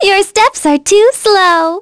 Cassandra-Vox_Skill6.wav